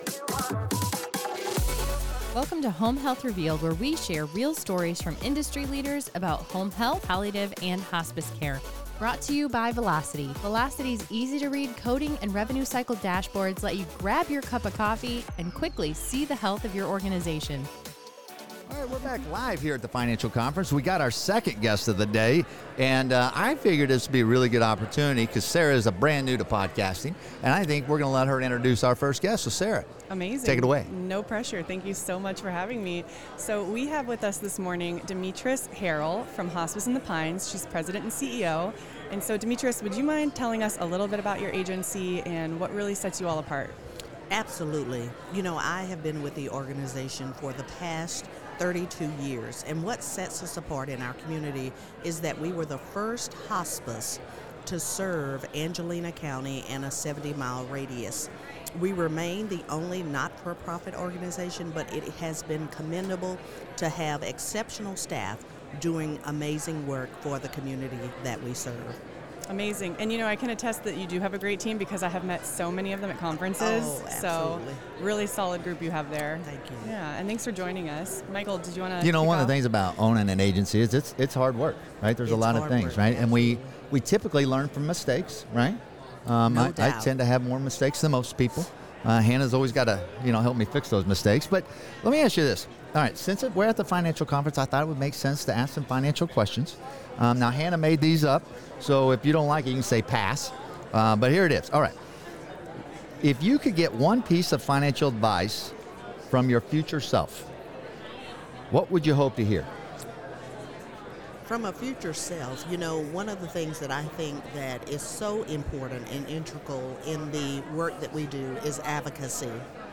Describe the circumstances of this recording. If you want a thoughtful, heartfelt, and perspective-rich conversation on leadership in hospice and home health, this is an episode you won’t want to miss.